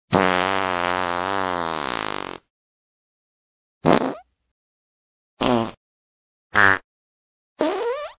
fart_2.ogg